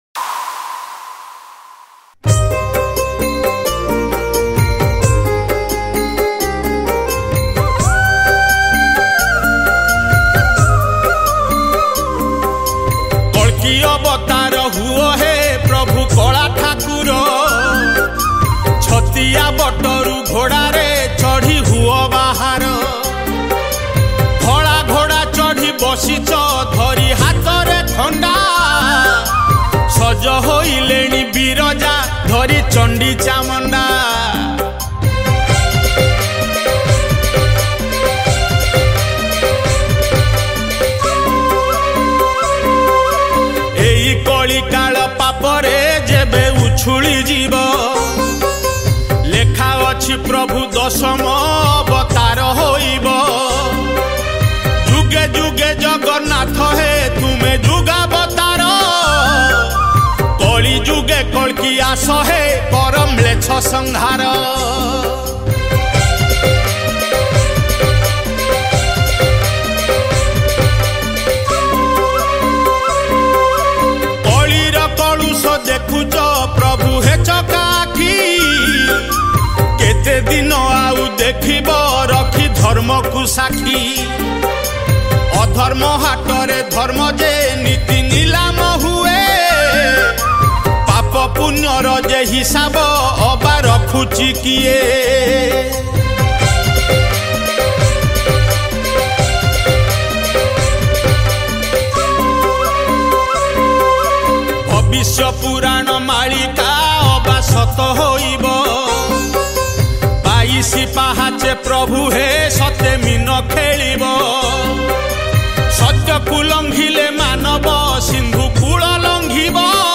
► Recorded At: BM Studio